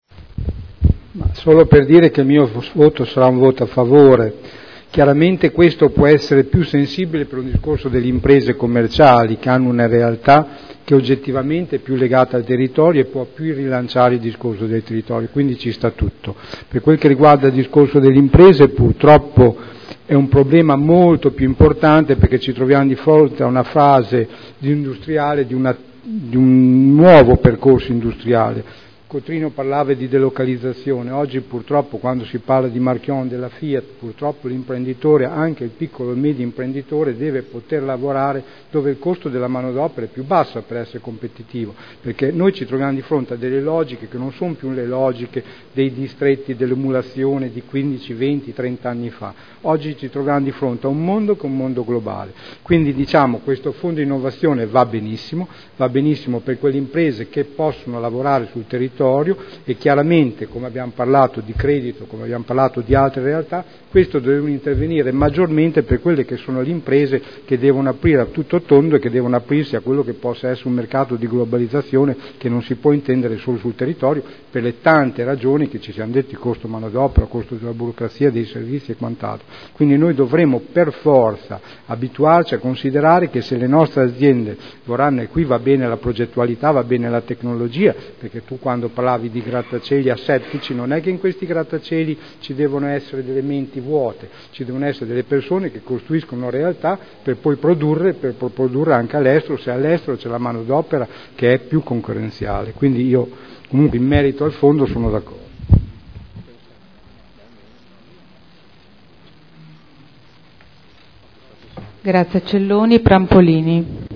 Seduta del 19/09/2011. Dichiarazione di voto proposta di deliberazione. Fondo provinciale per il sostegno all’innovazione delle imprese – Approvazione dello schema di convenzione per il rinnovo del fondo rotativo